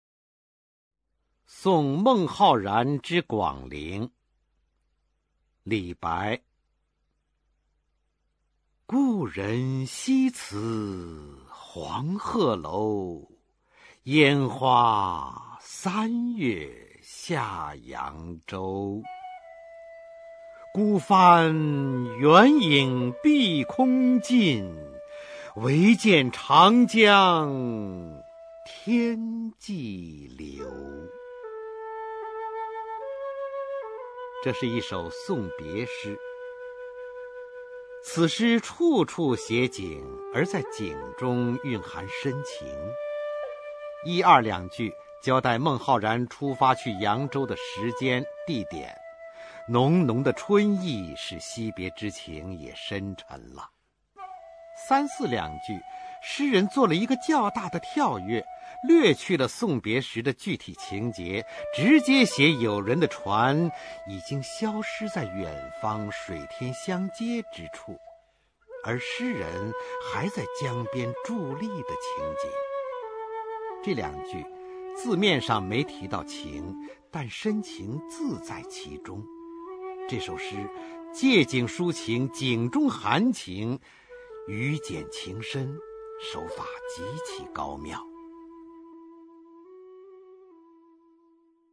[隋唐诗词诵读]李白-送孟浩然之广陵 唐诗吟诵